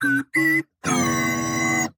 transforms
变形